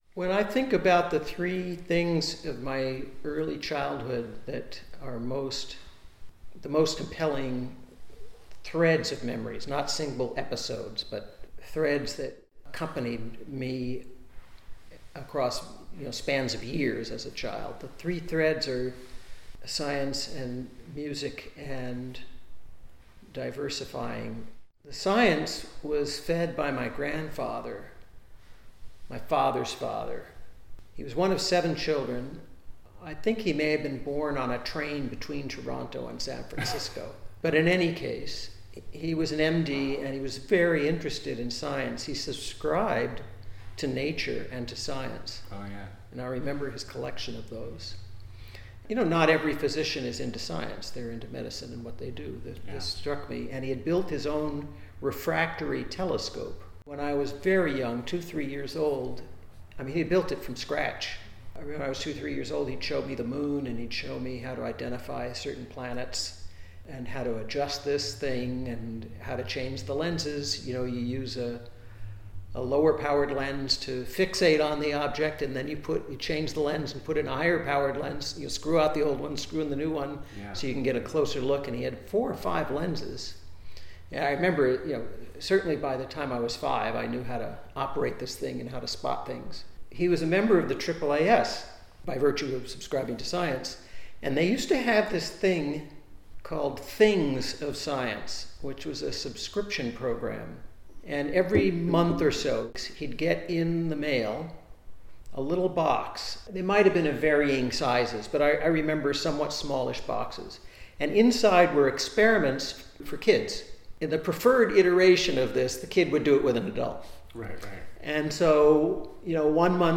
Like a few of the influential psychologists I’ve met along this journey, Dr. Levitin has conducted several very candid interviews in the past.